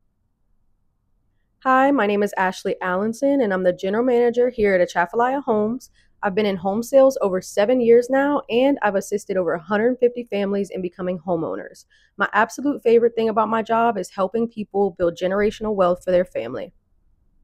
Voice Note